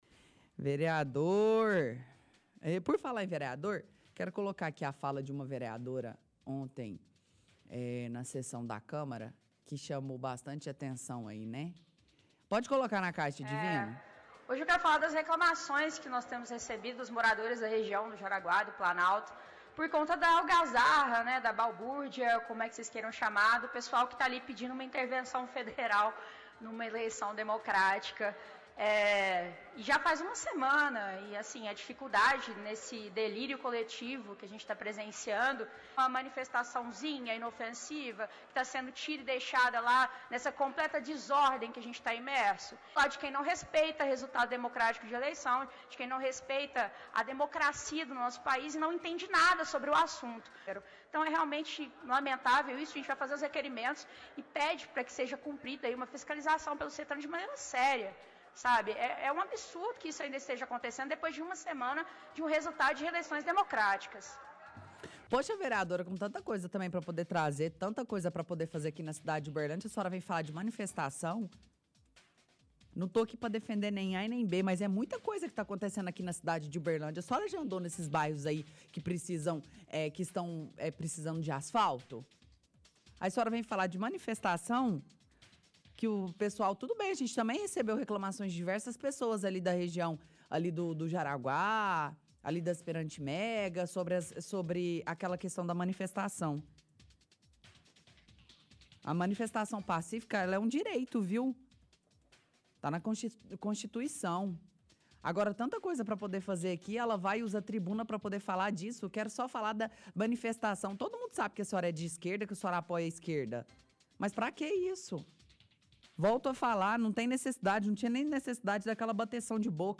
– Transmissão de áudio da vereadora Cláudia Guerra durante sessão da câmara reclamando de manifestações próximas ao quartel.